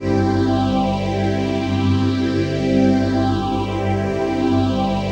PHASEPAD06-LR.wav